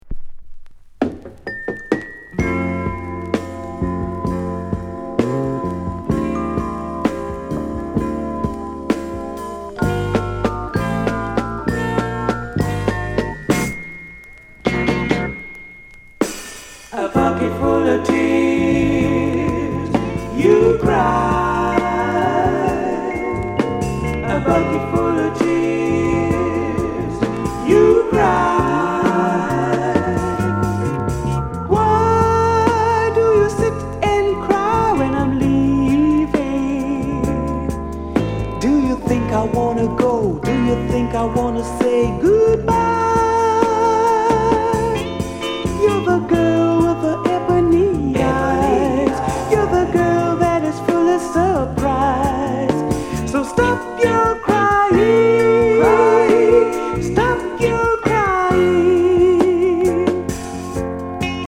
SOULFUL VOCAL